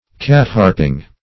Search Result for " cat-harping" : The Collaborative International Dictionary of English v.0.48: Cat-harping \Cat"-harp`ing\ n. (Naut.)